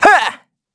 Evan-Vox_Attack2_kr.wav